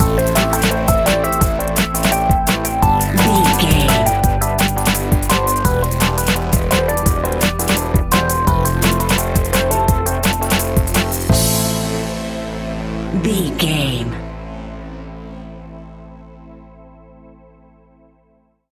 Aeolian/Minor
Fast
futuristic
hypnotic
industrial
frantic
aggressive
synthesiser
drums
electronic
sub bass